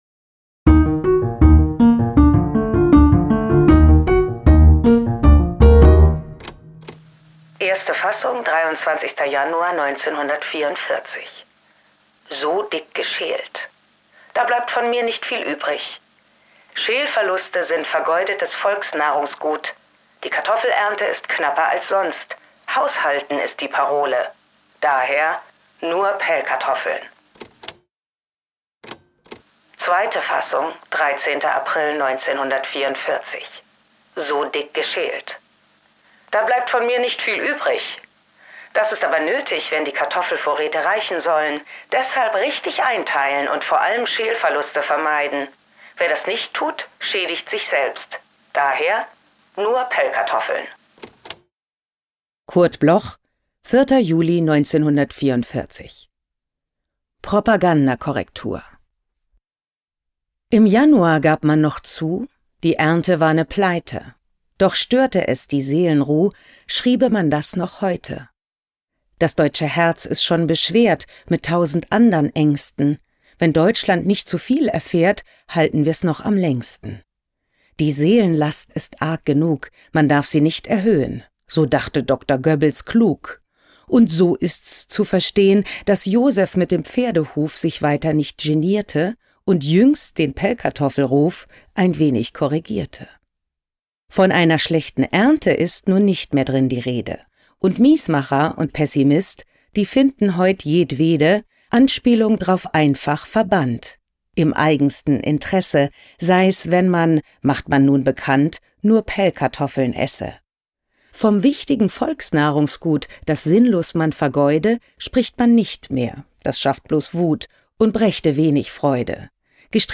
voorgedragen door Katharina Wackernagel
Katharina-Wackernagel-Propagandakorrektur_mit-Musik.m4a